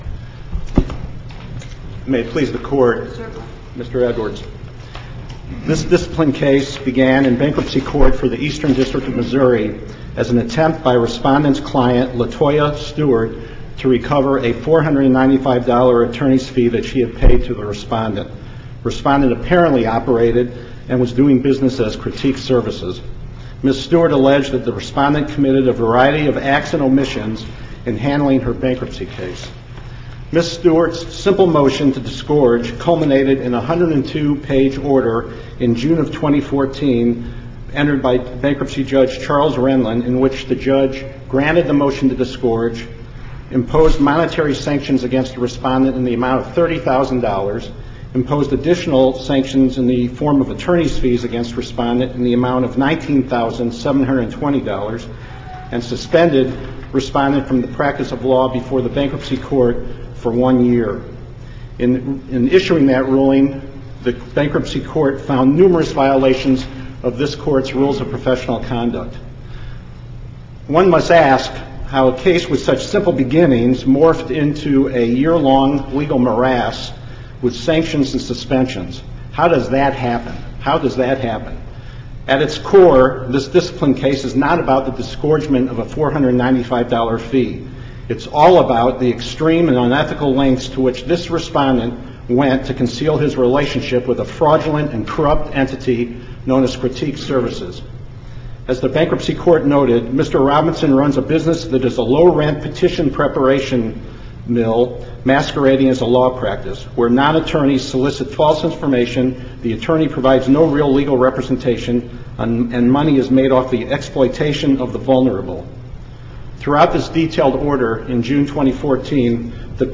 MP3 audio file of oral arguments in SC96016